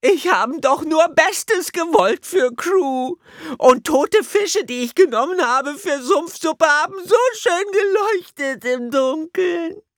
Sprachbeispiel des Sumpfsuppe köchelnden Sumpflingn